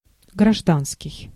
Ääntäminen
Ääntäminen US Tuntematon aksentti: IPA : /sɪˈvɪljən/